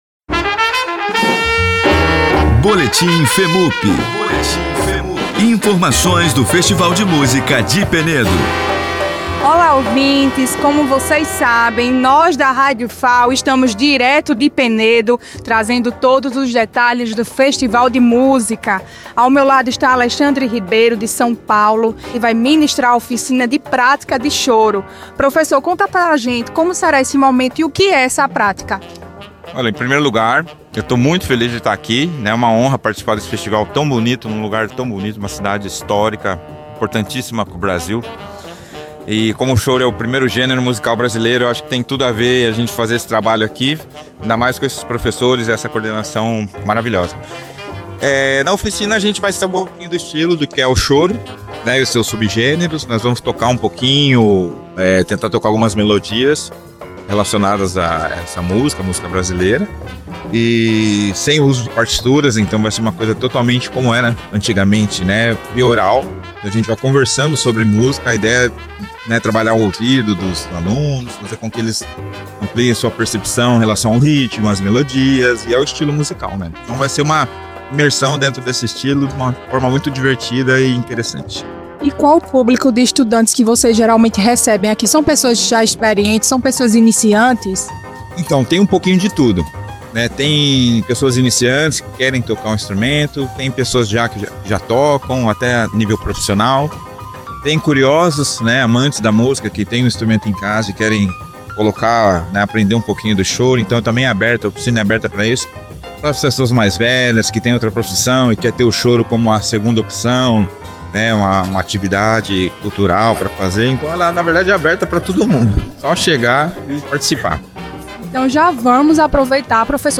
Ensaios instrumentais realizados na 16ª edição do Festival de Música de Penedo